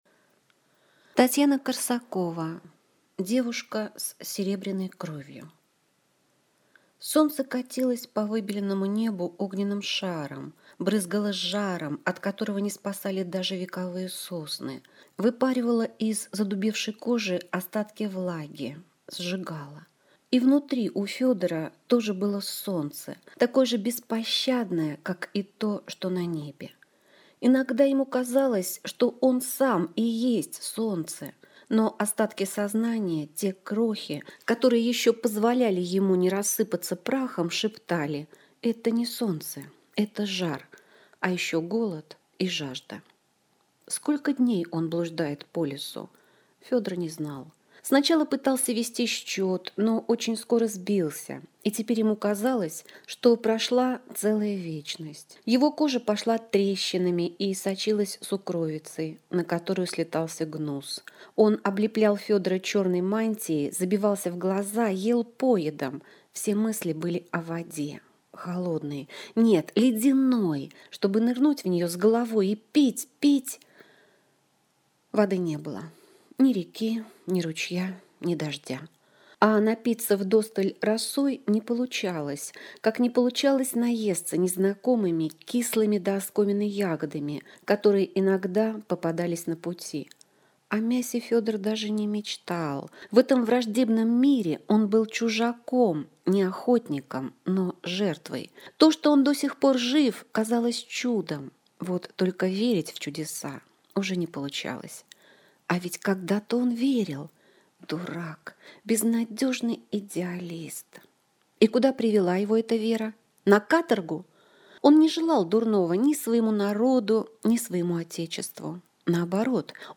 Аудиокнига Девушка с серебряной кровью - купить, скачать и слушать онлайн | КнигоПоиск